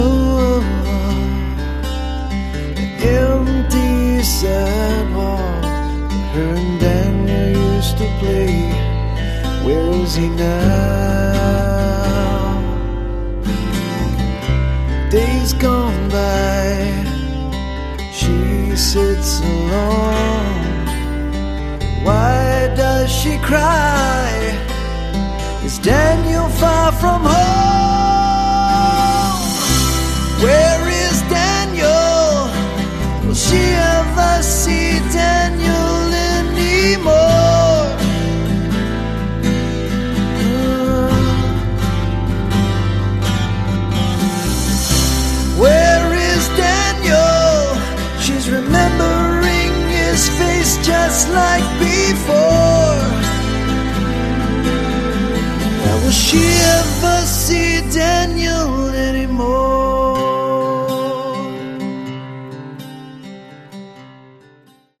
Category: AOR
a very sentimental ballad